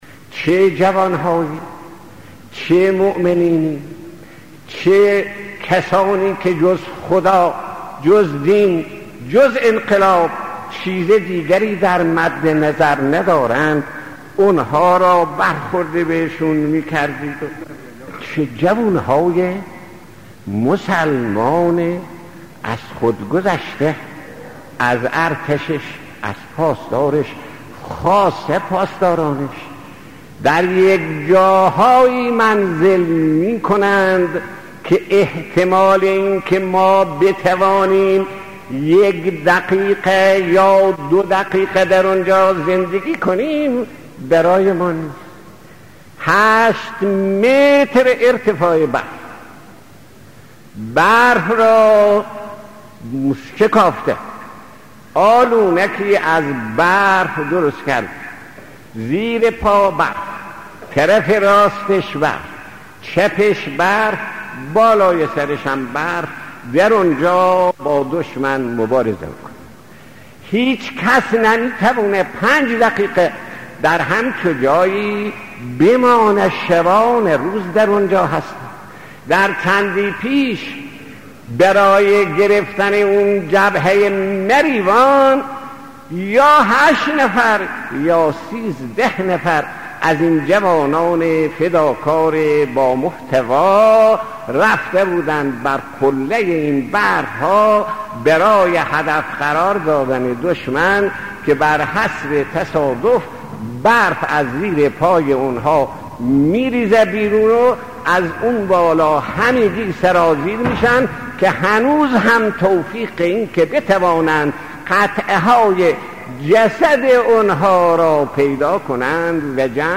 نوید شاهد یزد: بياناتي از شهيد آيت الله محمد صدوقى پيرامون فداکاری نیروهای جبهه ای